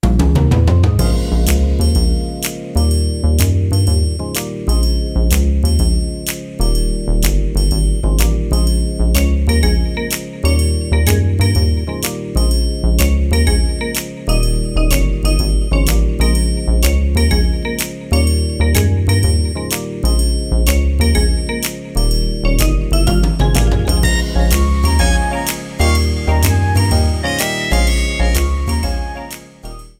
Ripped from the ISO
Faded in the end
Fair use music sample